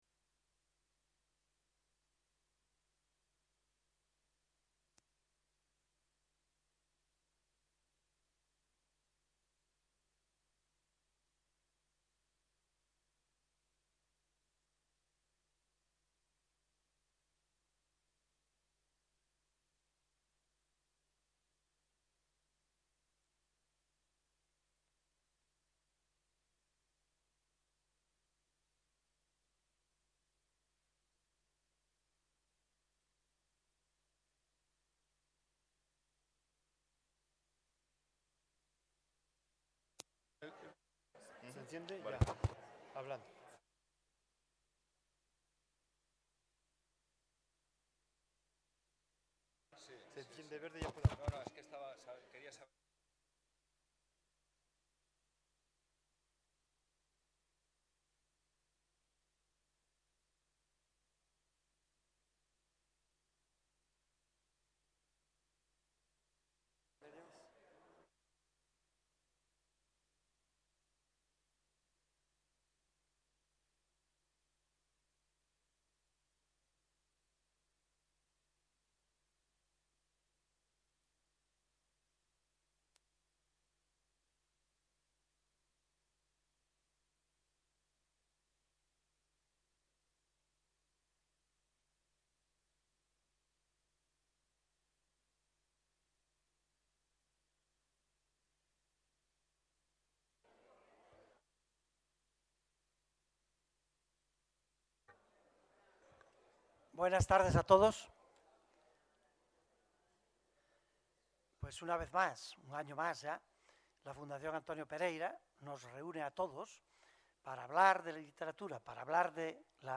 Mesa redonda organizada por la Fundación Antonio Pereira